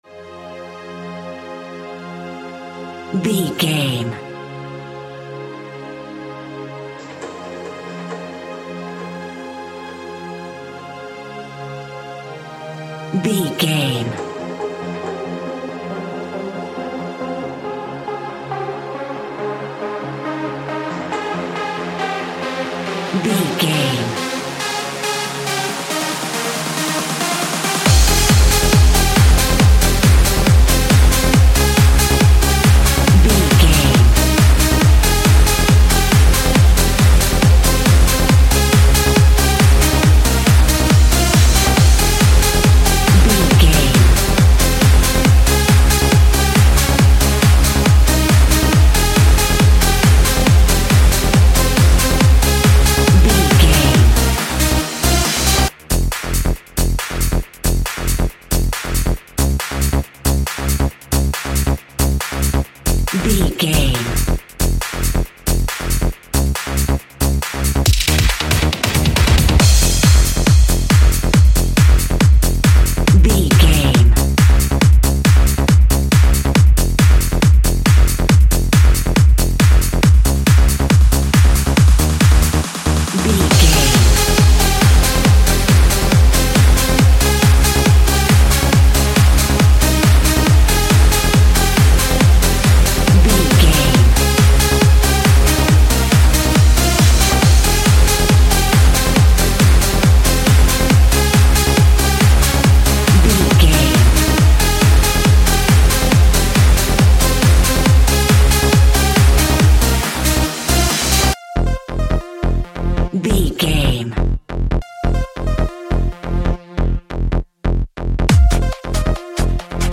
Trance Music.
Aeolian/Minor
heavy
energetic
uplifting
hypnotic
industrial
drum machine
synthesiser
acid house
acid trance
uptempo
synth leads
synth bass